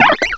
cry_not_emolga.aif